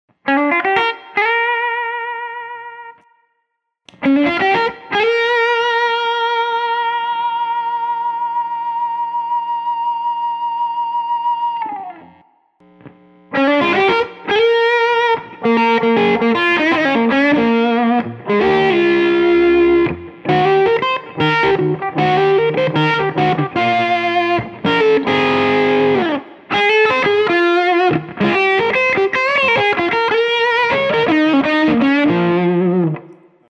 Category Distortion Pedal